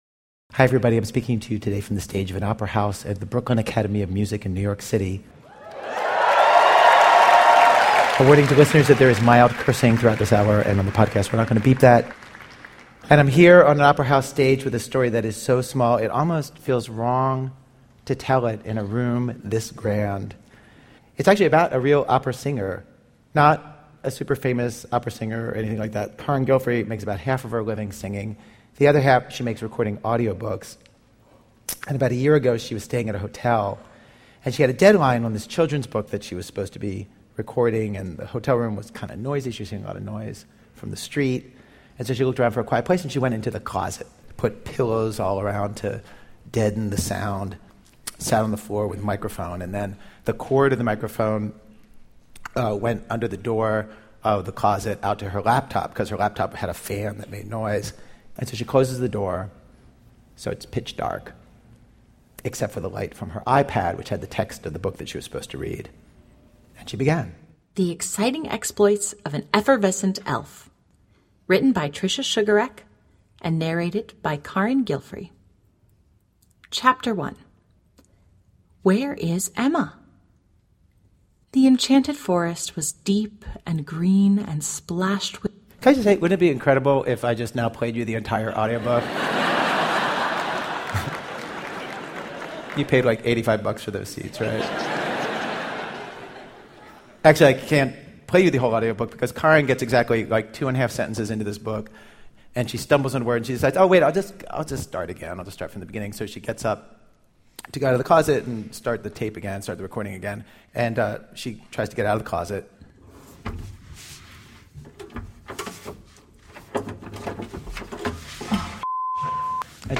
Our most ambitious live show ever!
We pulled together a massive team of theater pros at the Brooklyn Academy of Music's Opera House – nearly 50 singers, actors, dancers and musicians.
Journalism turned into a Broadway musical (the cast album is here), into opera. Mike Birbiglia, Sasheer Zamata, Stephin Merritt, Josh Hamilton, Lindsay Mendez, Lin-Manuel Miranda and others.